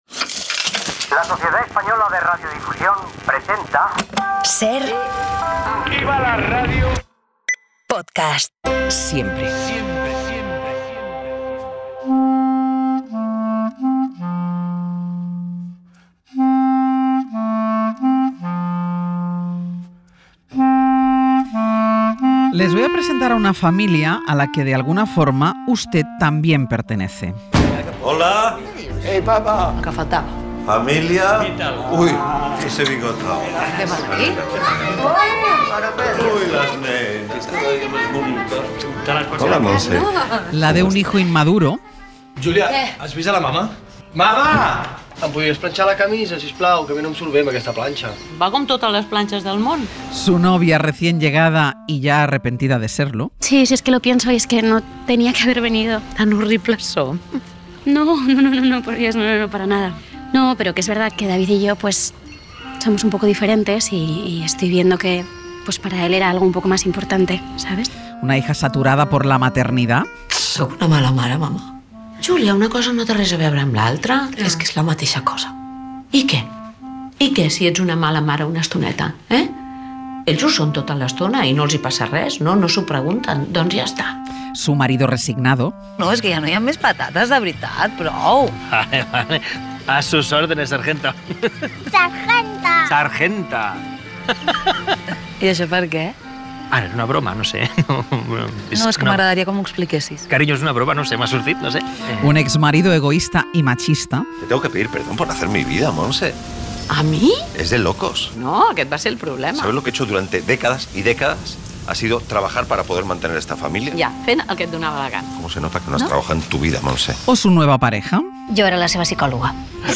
Esta mañana ha visitado el Hoy por Hoy para hablarnos de esta última película, que está en los cines y que dará que hablar...